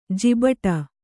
♪ jibaṭa